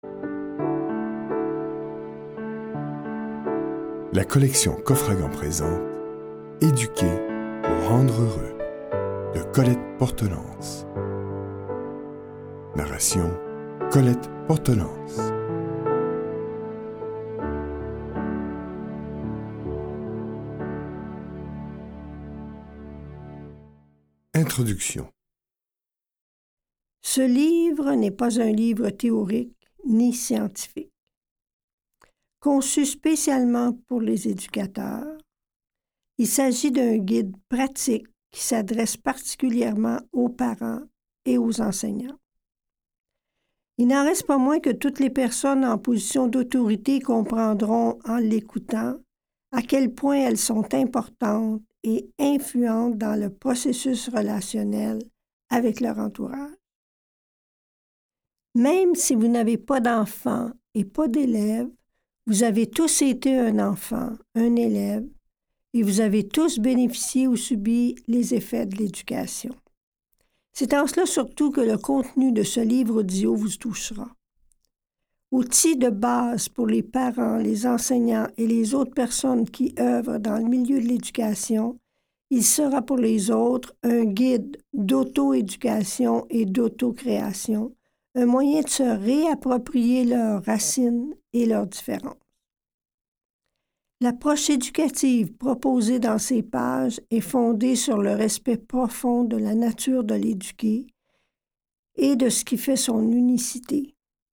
0% Extrait gratuit Disponible en ebook Eduquer pour rendre heureux 12 , 99 € Éduquer pour rendre heureux de Colette Portelance Éditeur : Coffragants Paru le : 2009 Éduquer un être humain pour le rendre heureux, c’est lui apprendre à être lui-même, à être en relation avec les autres, à être créateur de sa vie, de ses rêves et du monde.